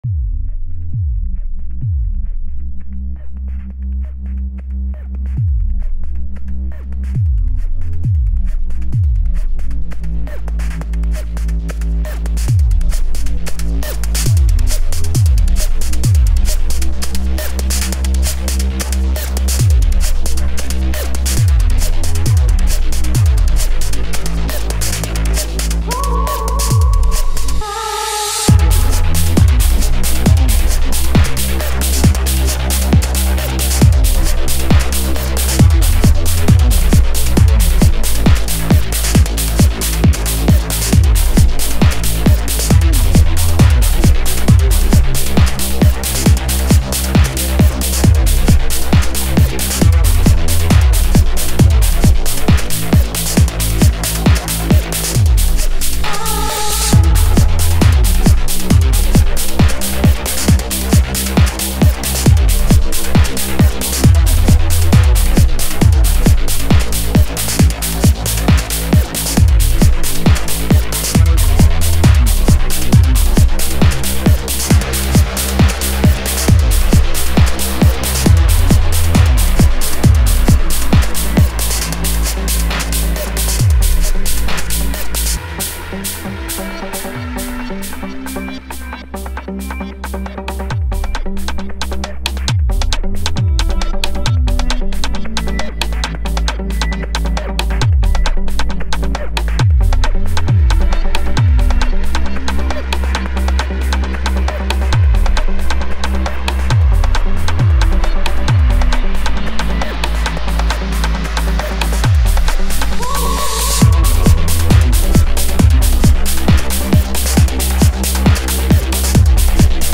объединяющее элементы электронной музыки и техно.